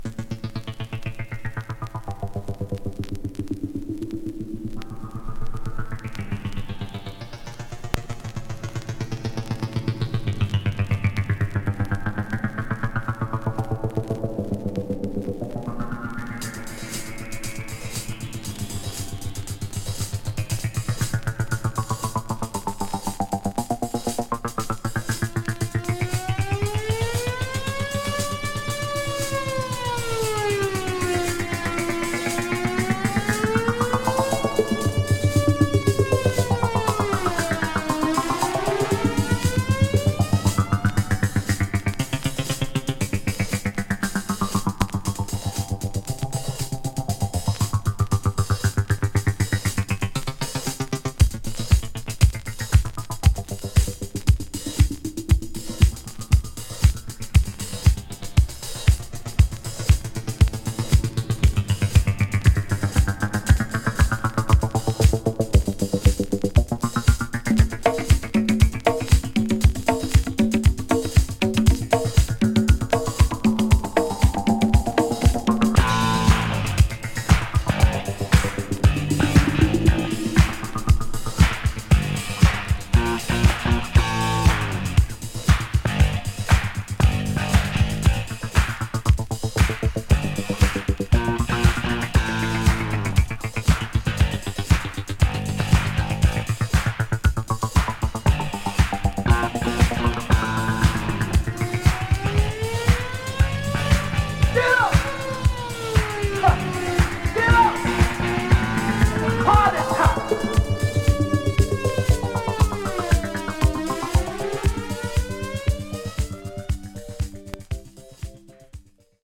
media(A/B): VG/VG スリキズによるチリノイズ、レーベルにシール
【DISCO】【BOOGIE】